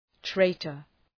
Προφορά
{‘treıtər}